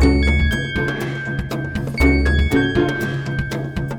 Pensive (Full) 120BPM.wav